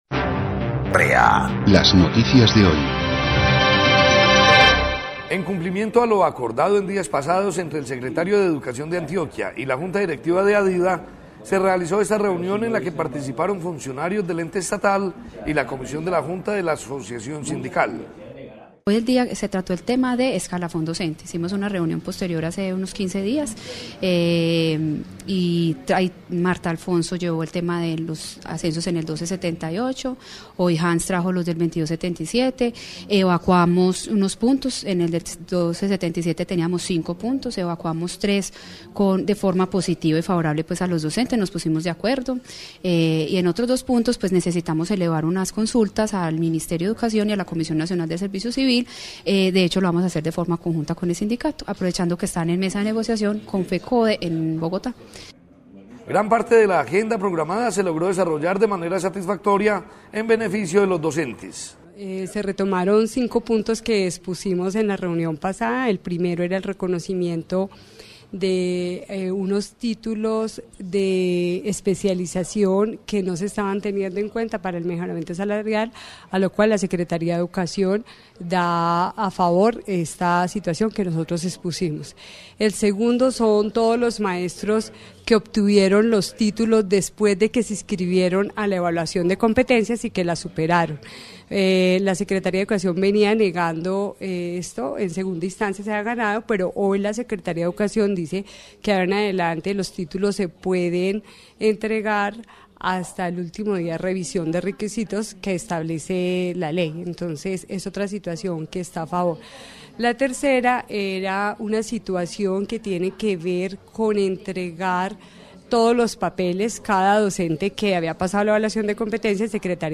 Tomado del programa Despertar Educativo TV de Adida el pasado Domingo 22 de marzo de 2015